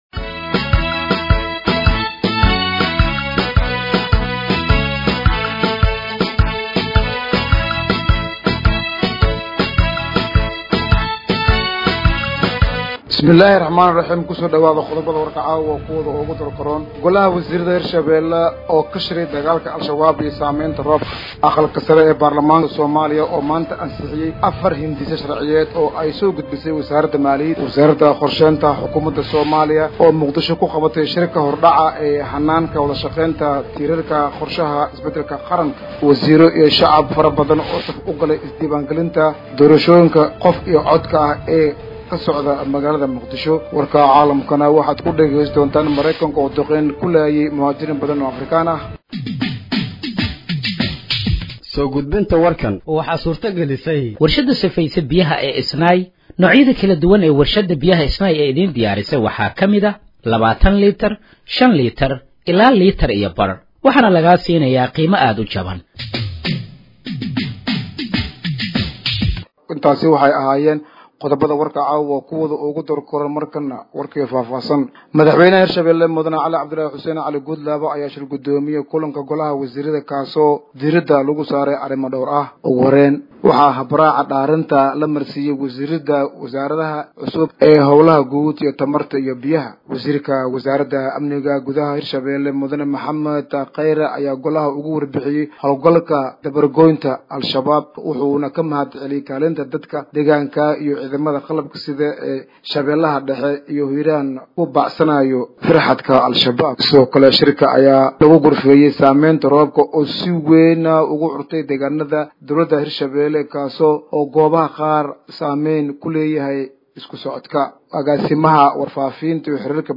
Dhageeyso Warka Habeenimo ee Radiojowhar 28/04/2025